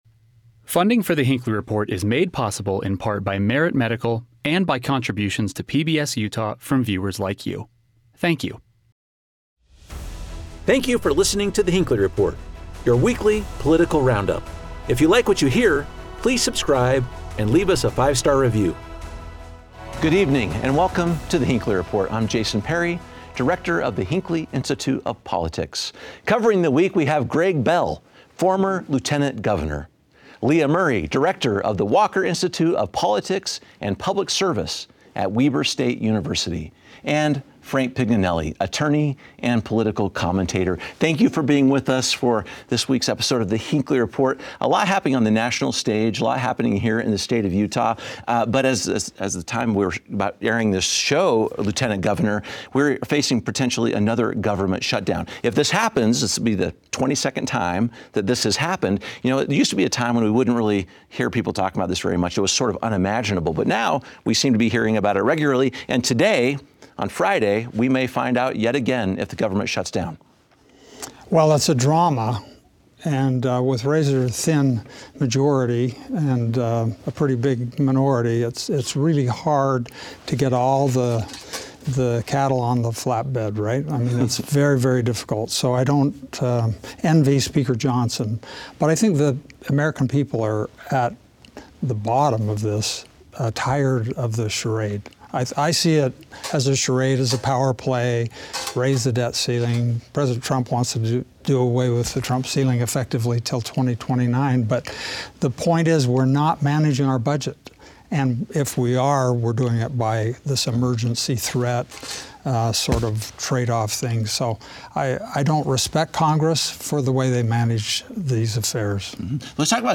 As a divided Congress struggles to keep the federal government open and funded, our expert panel examines how bipartisanship can work in Washington.